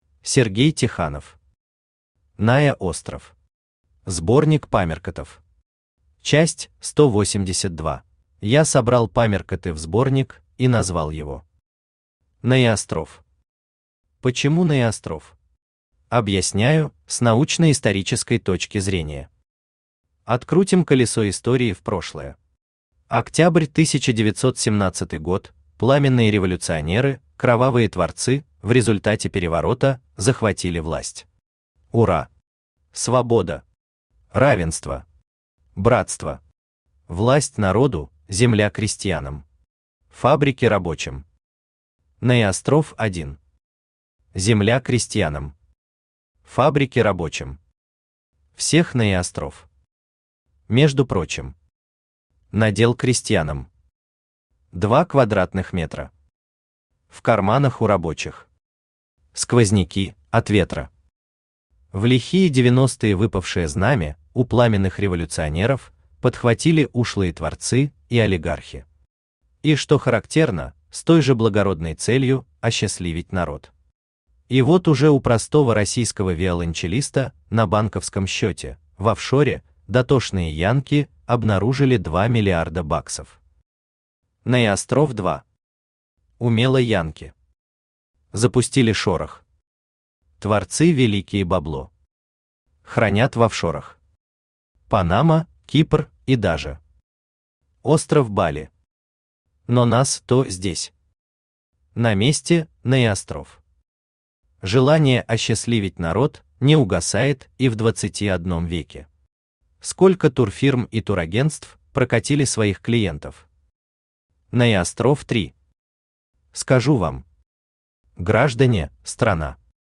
Аудиокнига НаеОстров. Сборник памяркотов. Часть 182 | Библиотека аудиокниг
Часть 182 Автор Сергей Ефимович Тиханов Читает аудиокнигу Авточтец ЛитРес.